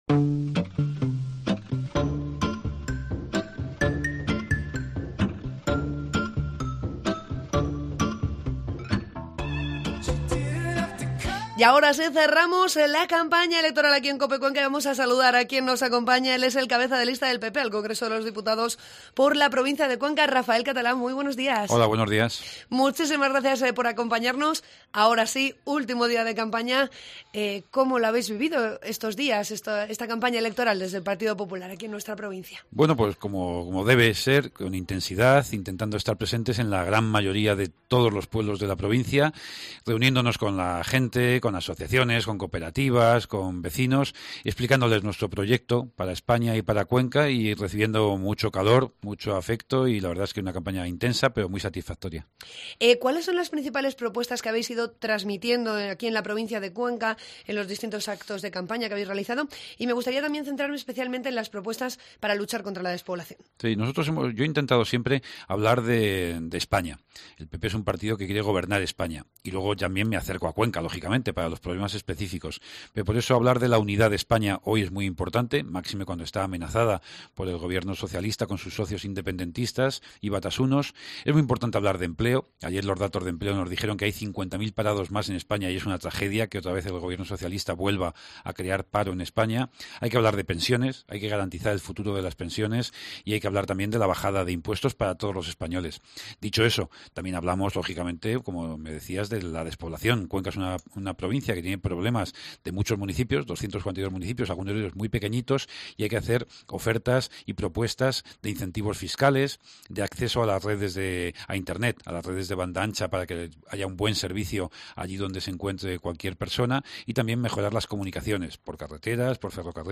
Entrevista con Rafael Catalá, candidato del PP al Congreso por la provincia de Cuenca